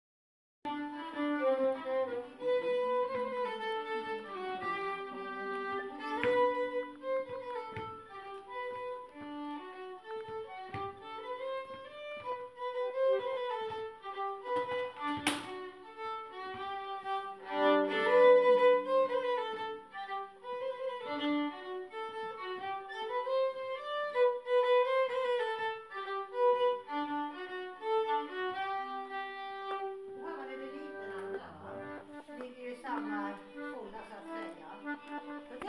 Schottis från Dorotea